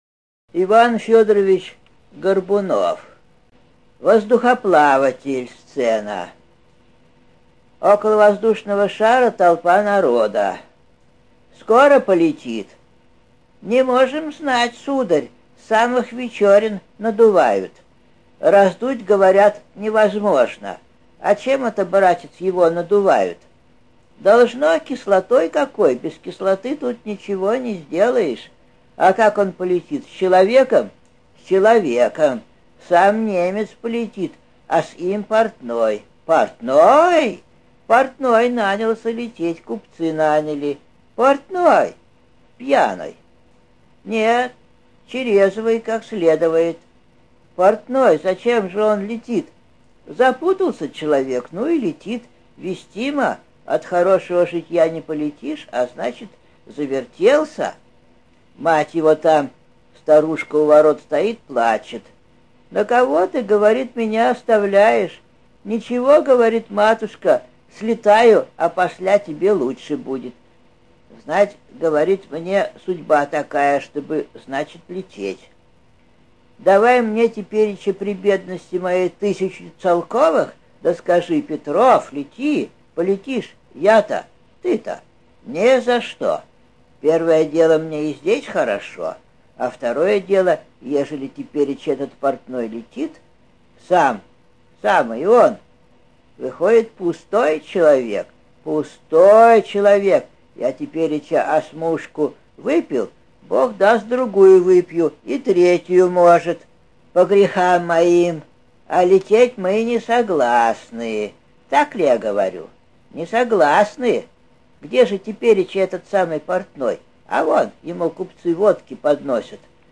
сцена